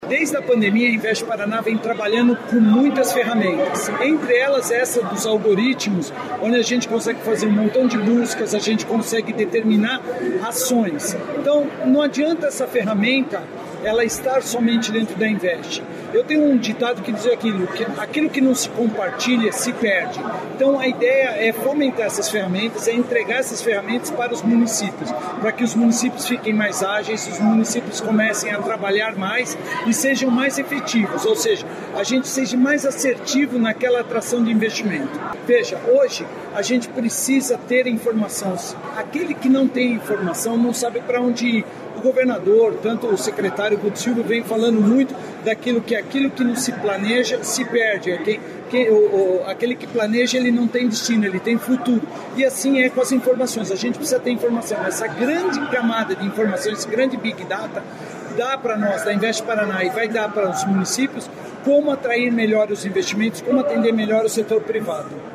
Sonora do presidente da Invest Paraná, Eduardo Bekin, sobre a liberação de R$ 13,8 milhões para inovação e acessibilidade nos municípios